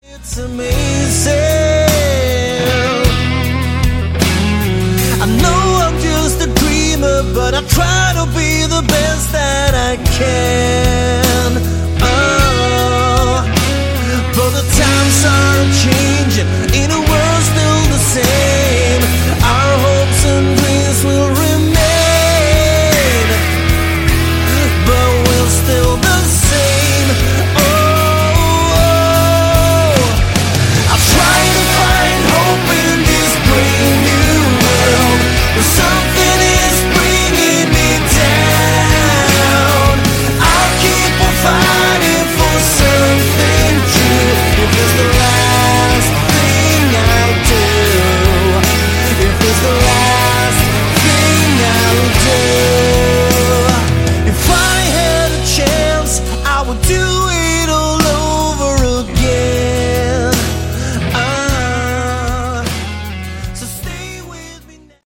Category: Melodic Rock
Lead Vocals, Guitar
Drums, Percussion
Bass